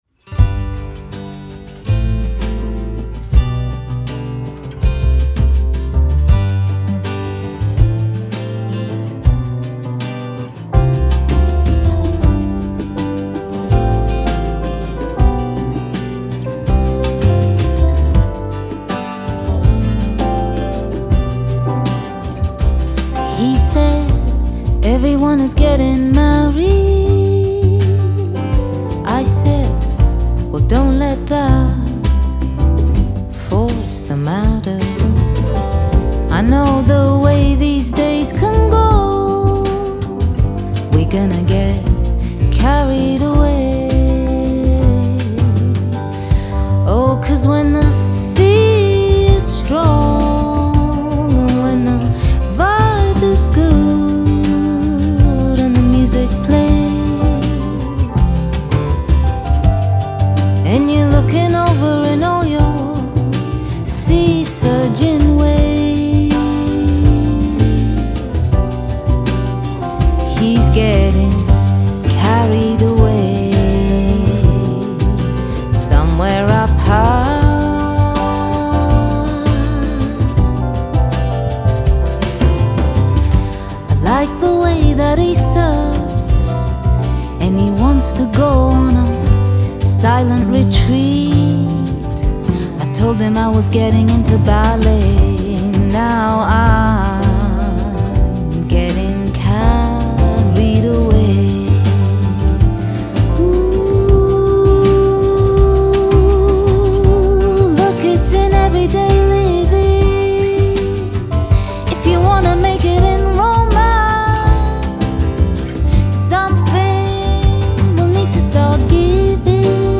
Genre : Alternative, Folk